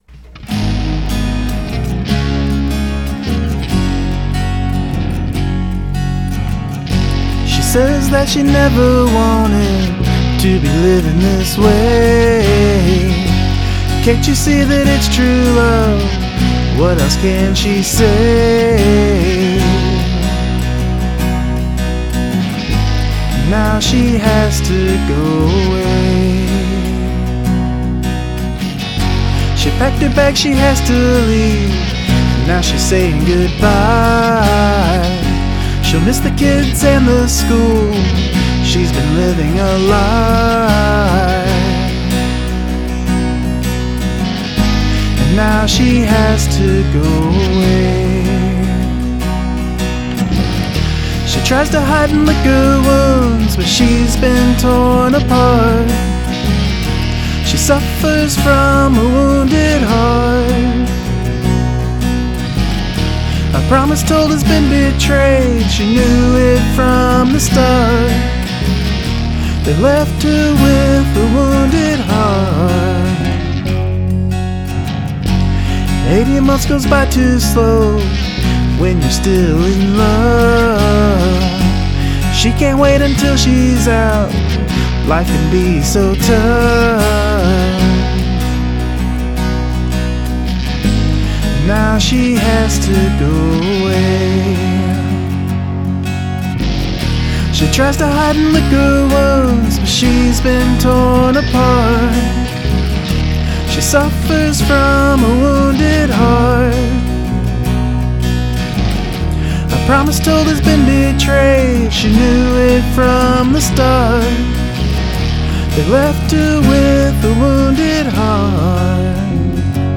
Recorded: BR900
Yamaha acoustic/electric recorded line in and with AT2020
One track panned hard right, one hard left.
AT2020 for vox
The electric and acoustic guitar works well together!